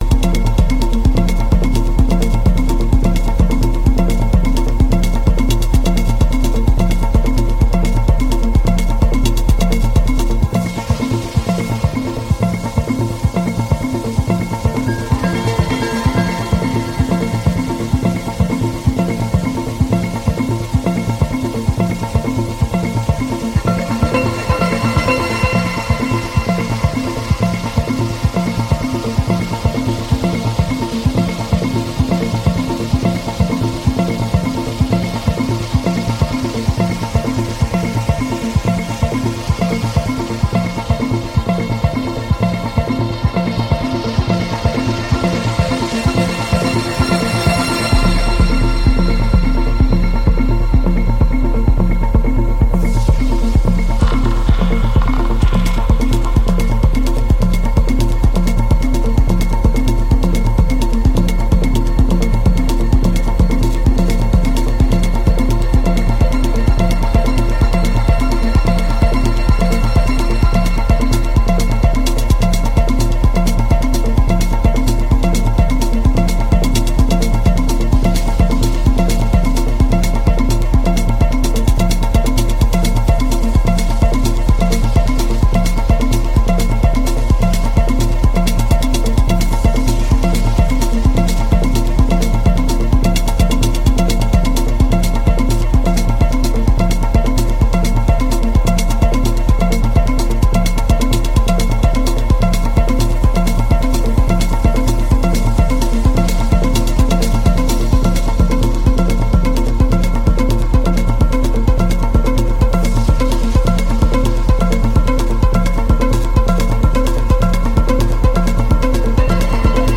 Мелодичное эмоциональное техно с трансовыми синтезаторами.